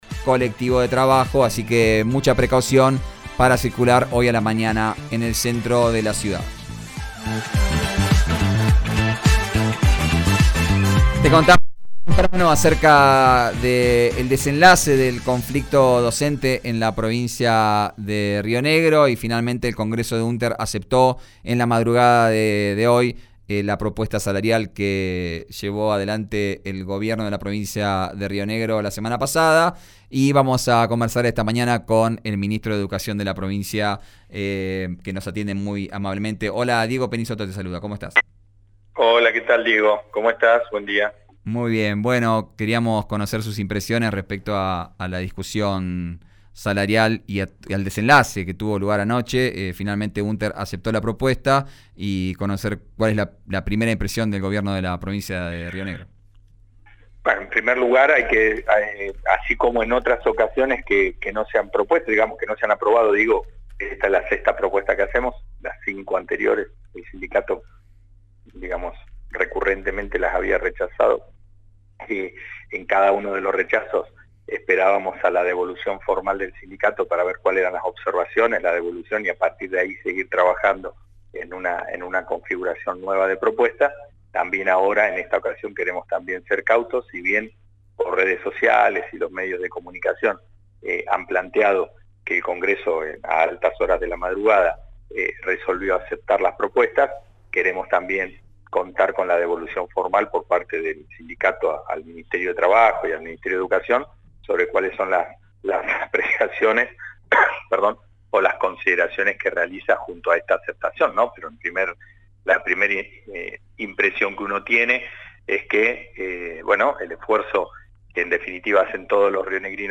El ministro de Educación de Río Negro, Pablo Núñez -en comunicación con «Vos al Aire» por RÍO NEGRO RADIO- reflexionó sobre todas las etapas donde se fueron profundizando las protestas docentes y opinó sobre la última resolución gremial de la Unter.